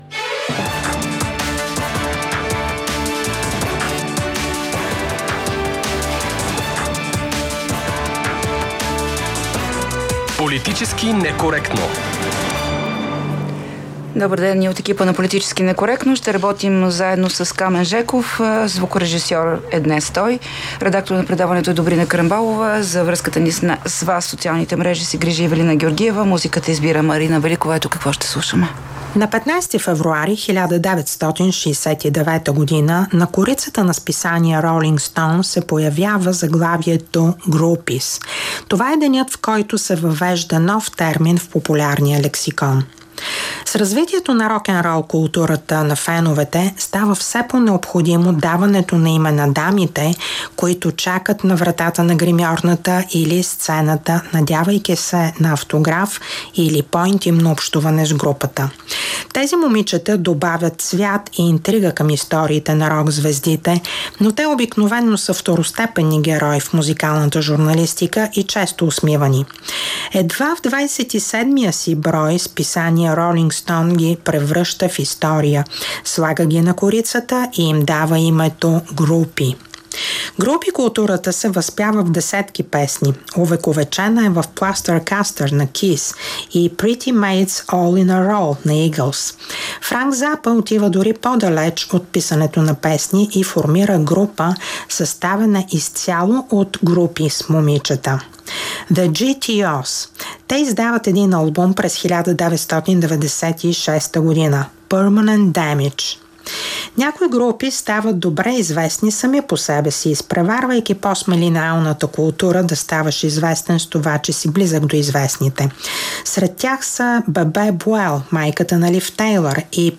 ▪ За цените и финансовото състояние на държавата, както и за съдбата на лявото, след като БСП влезе в коалиция с десни и центристки формации, говорим с бившия заместник-финансов министър, бивш депутат от БСП, а сега лидер на Партия „Нормална държава“ Георги Кадиев.